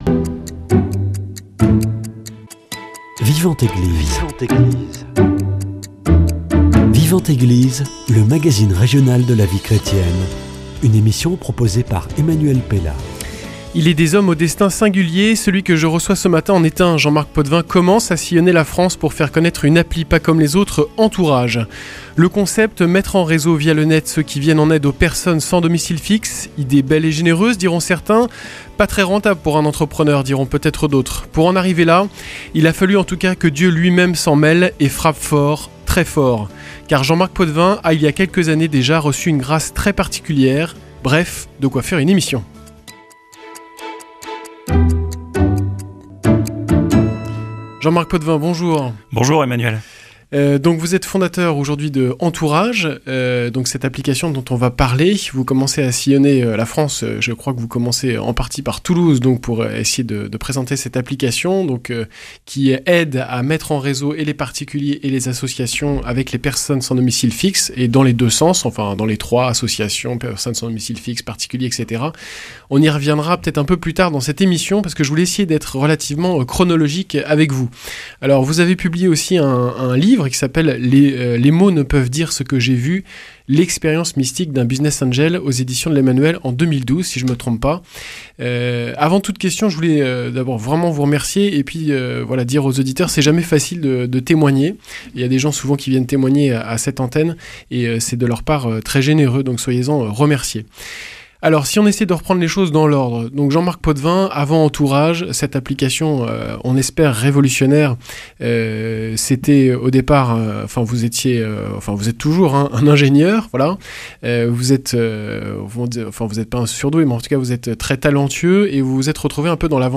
Celui de mon invité de ce matin en est un.